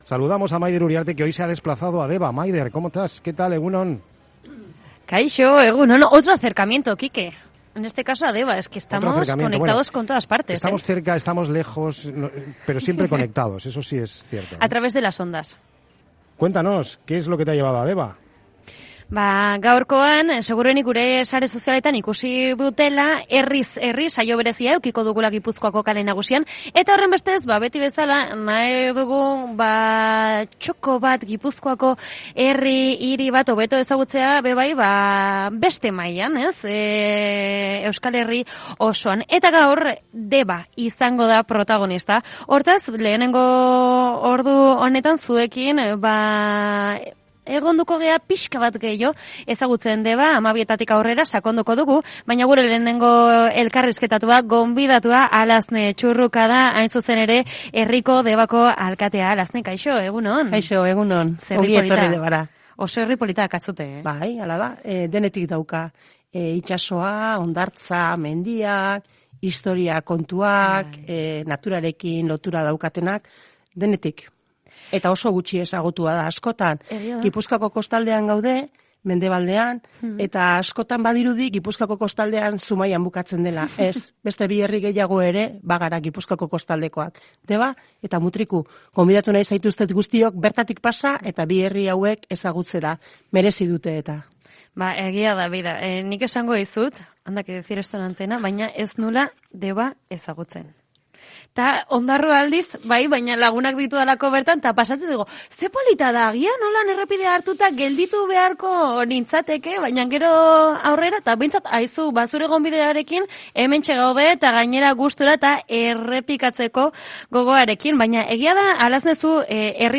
Herriz Herri Deba: Alazne Txurrukarekin hizketan, Debako alkatea - Onda Vasca